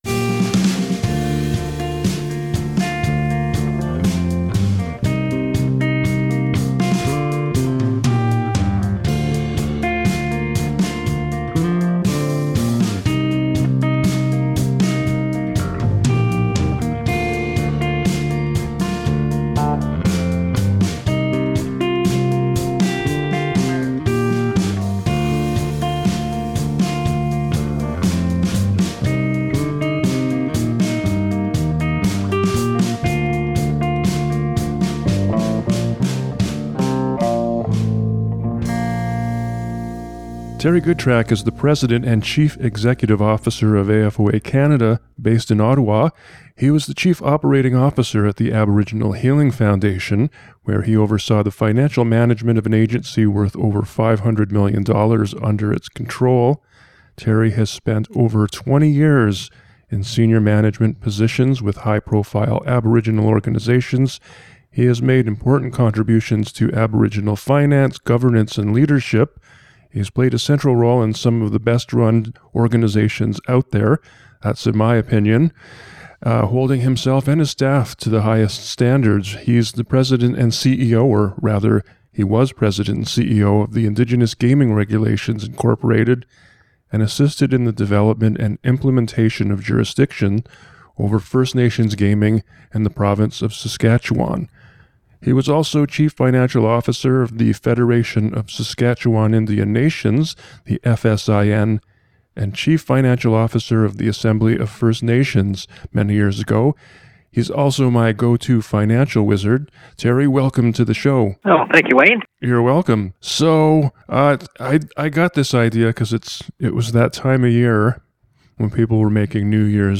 In November, it will have been 20 years since the Royal Commission on Aboriginal Peoples issued its multi-volume final report. In this audio clip, former RCAP Co-Chair, Georges Erasmus, renews the call to make those recommendations a reality.